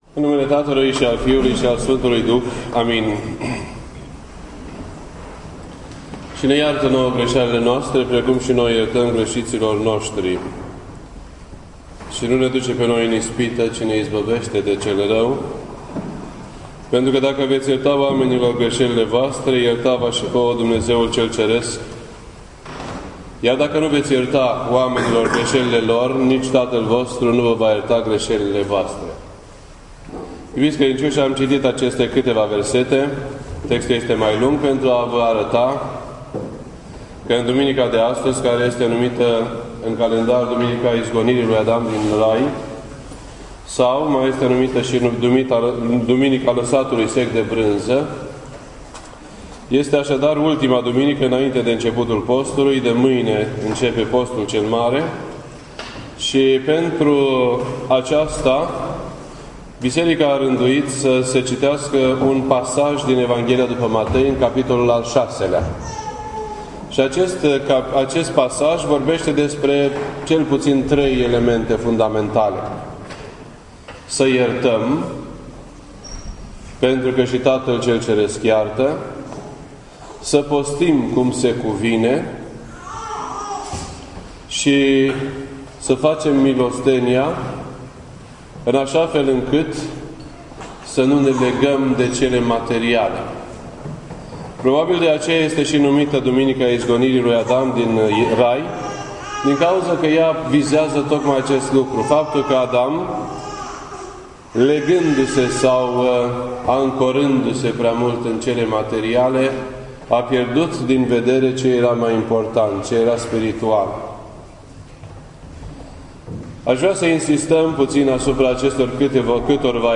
This entry was posted on Sunday, February 23rd, 2014 at 12:12 PM and is filed under Predici ortodoxe in format audio.